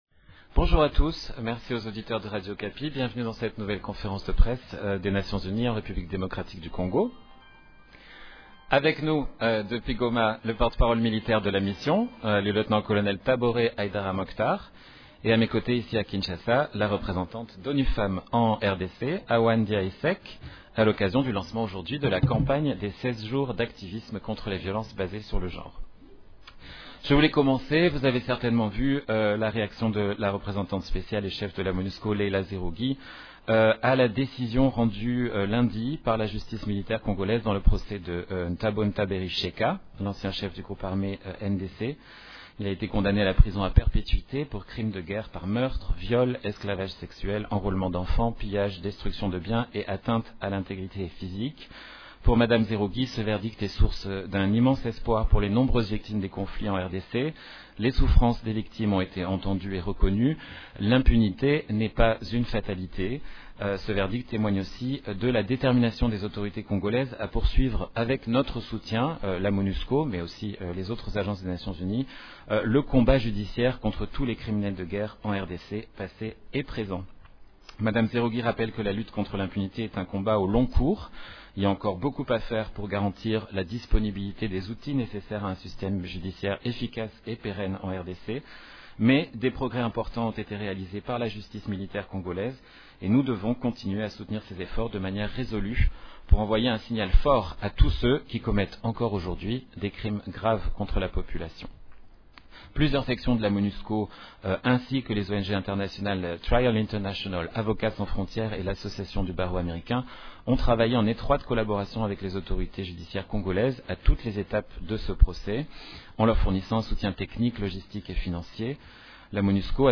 Conférence de presse de l’ONU en RDC du mercredi 25 novembre 2020